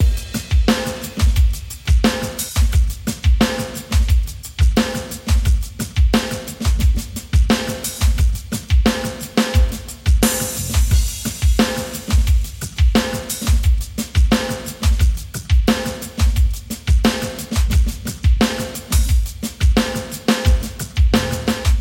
沉重的脚槽
描述：混合了演奏的旋律和编程的节拍。由Roland TD25鼓组演奏和录制。
标签： 88 bpm Rock Loops Drum Loops 3.67 MB wav Key : Unknown
声道立体声